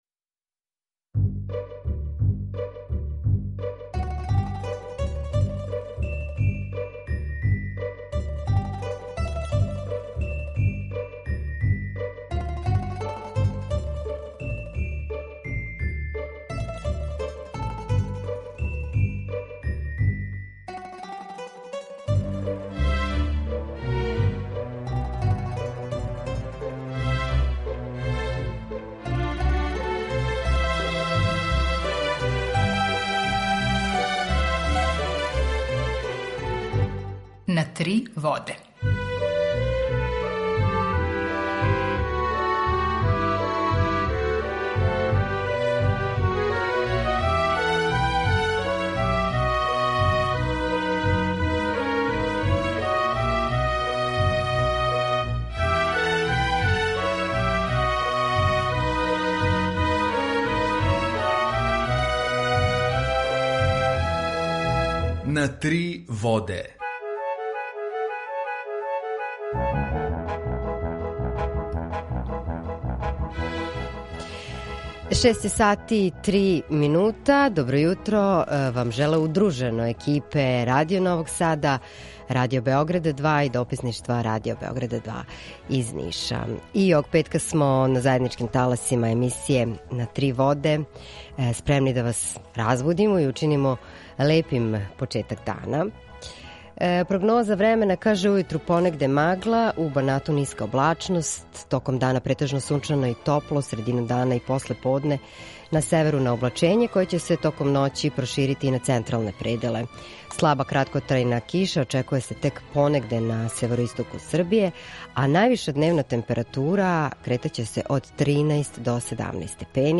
Емисију реализујемо уживо заједно са Радиом Републике Српске у Бањалуци и Радио Новим Садом
У два сата, ту је и добра музика, другачија у односу на остале радио-станице.